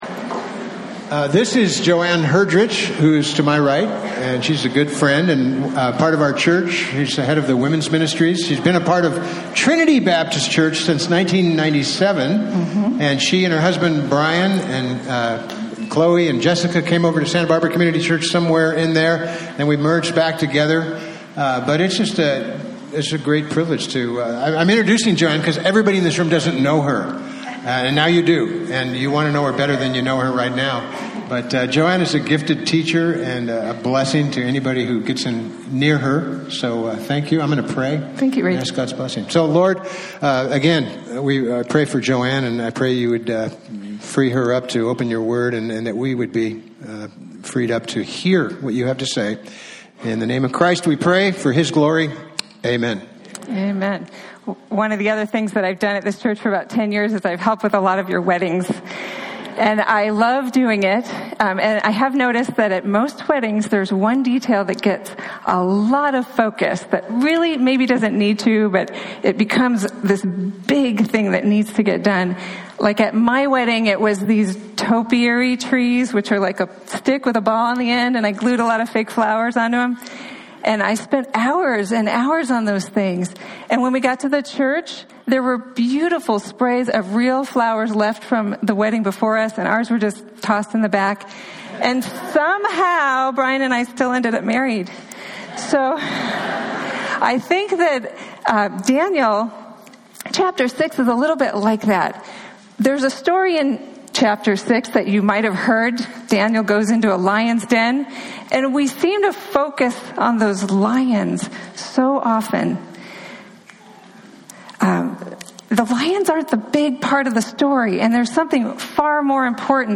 Daniel 6:1-28 Service Type: Sunday Topics